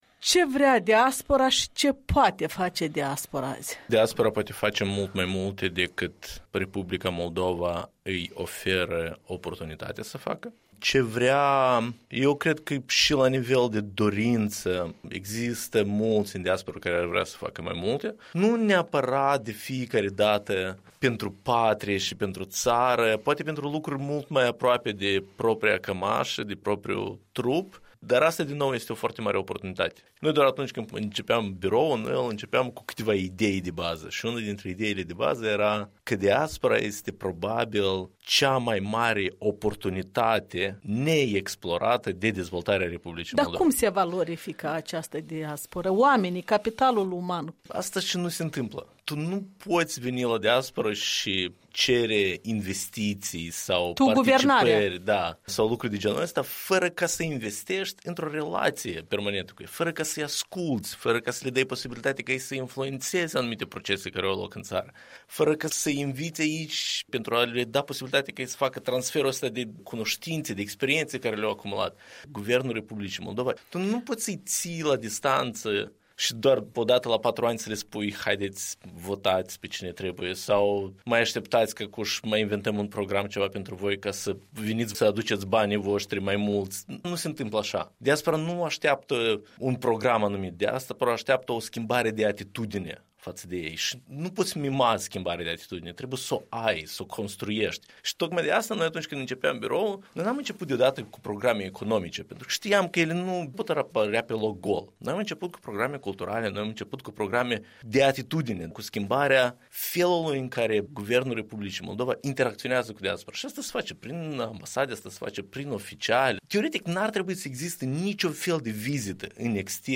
Un interviu bilanț despre investiții și diasporă, cu un fost director al Biroului pentru Diaspora moldovenească.